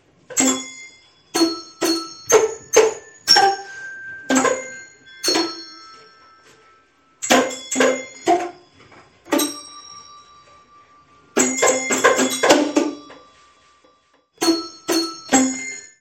Sobreposant la imatge que defineix la nostra masia sobre un pentagrama, hem pogut analitzar i tocar les “notes” corresponents. Ho hem fet amb les campanes i els Boomwhachers i el resultat final…